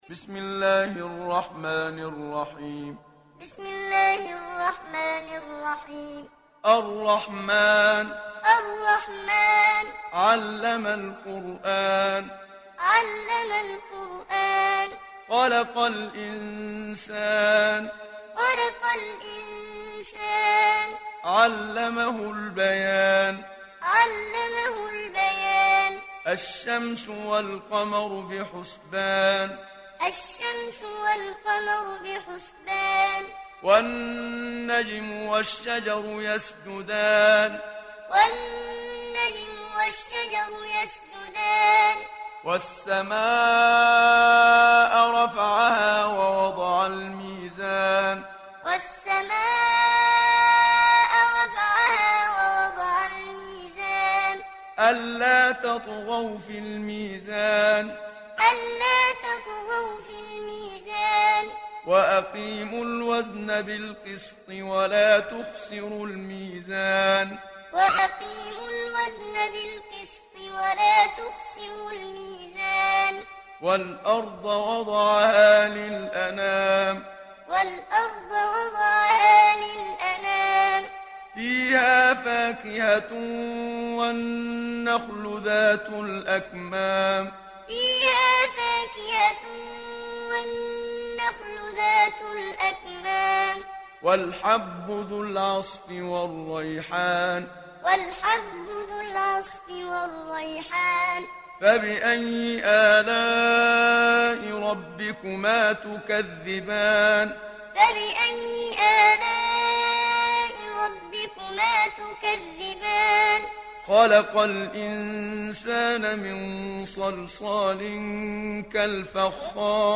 Download Surat Ar Rahman Muhammad Siddiq Minshawi Muallim